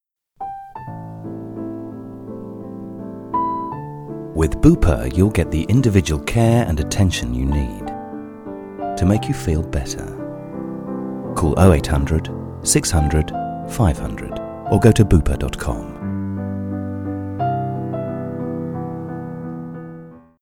Profi-Sprecher Britisch-Englisch.
britisch
Sprechprobe: eLearning (Muttersprache):